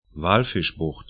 Pronunciation
Walfischbucht 'va:lfɪʃbʊxt Walvis Bay 'wɔ:lvɪs 'beɪ en Gebiet / region 22°59'S, 14°31'E